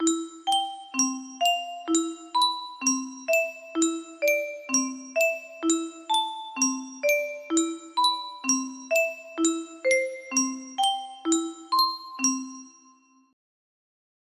Unknown Artist - Untitled music box melody
Hey! It looks like this melody can be played offline on a 20 note paper strip music box!